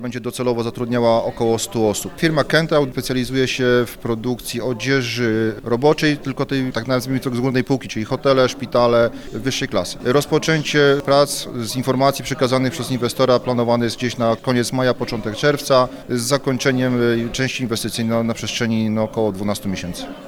Kosztem około 10 mln zł wybudować chcą one nowoczesną szwalnię, która, jak informuje wiceburmistrz Łobza Krzysztof Czerwiński, stworzy jak na lokalne warunki sporo miejsc pracy.